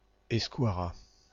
Ääntäminen
Synonyymit basque euskera euskara Ääntäminen Tuntematon aksentti: IPA: /e.sky.a.ʁa/ Haettu sana löytyi näillä lähdekielillä: ranska Käännöksiä ei löytynyt valitulle kohdekielelle. Samankaltaisia sanoja escrime escroc escroquer espace espace cosmique espace-temps espadon Määritelmät Substantiivit (fr) Langue basque.